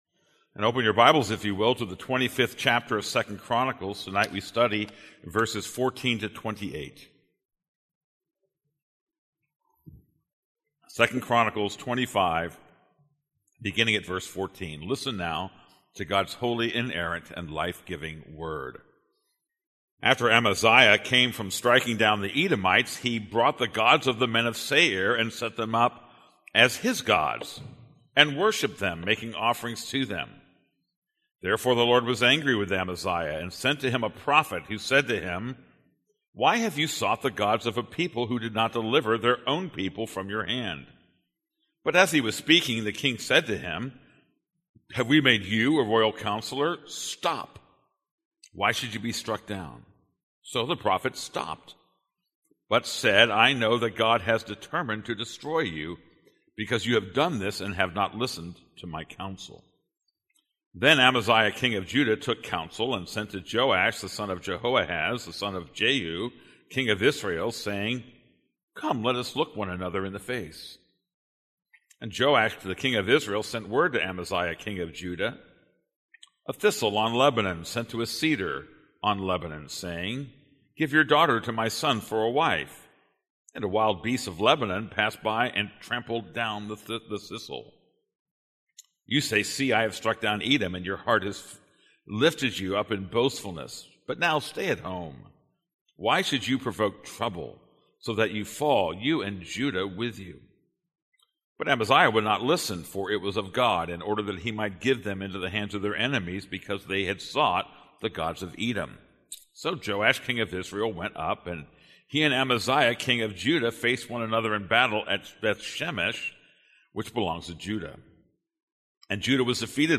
This is a sermon on 2 Chronicles 25:14-28.